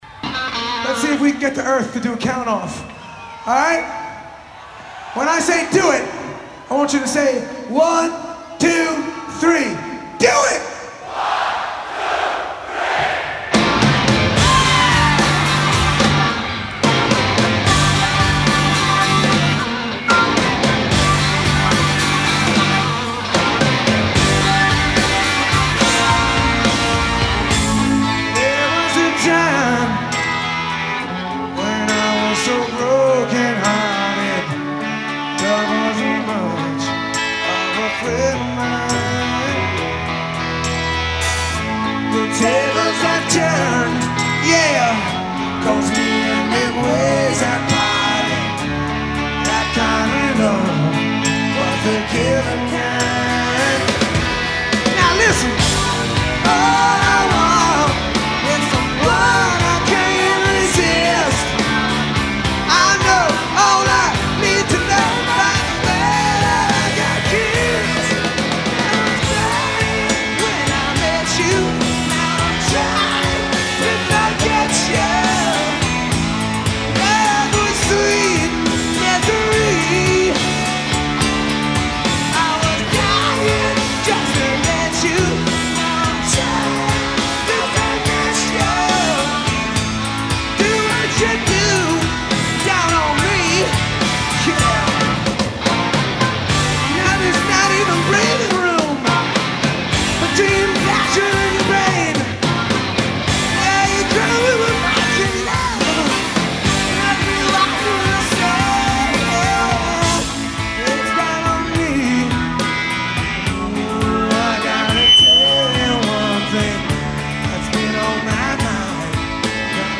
through a live version of the megahit